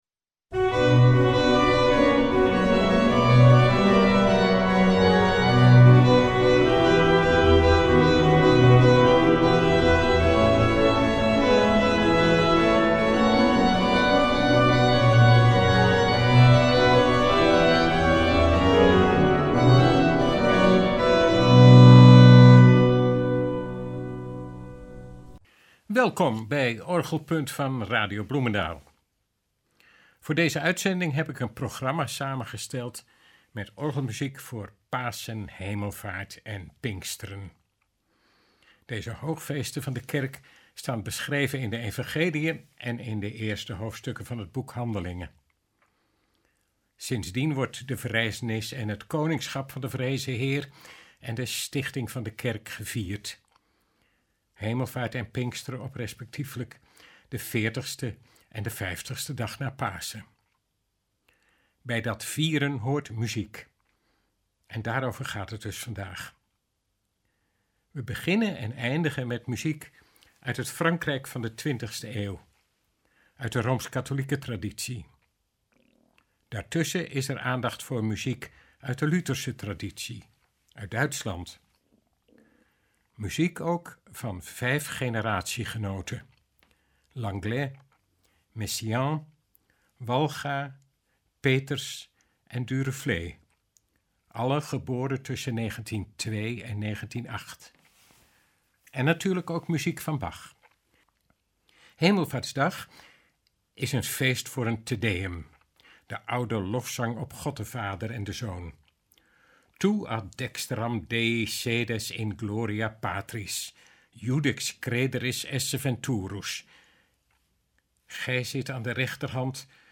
orgelmuziek
een historische opname